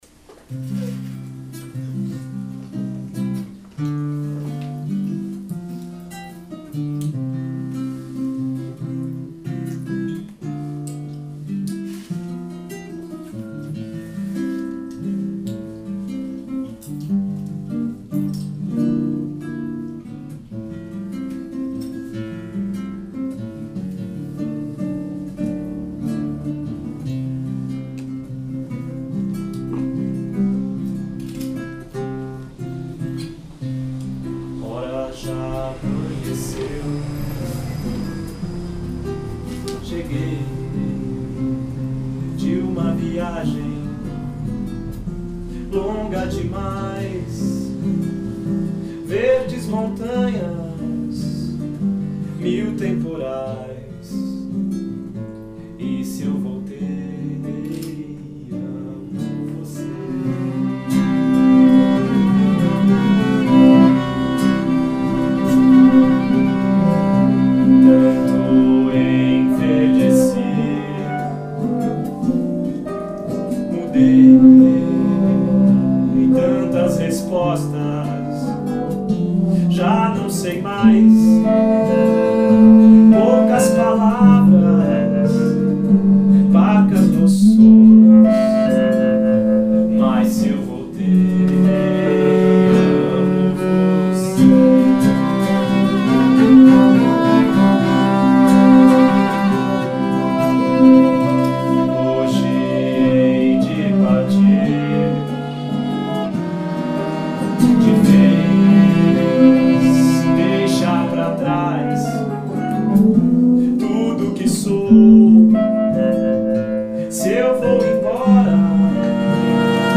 Apresentação na Jambrohouse.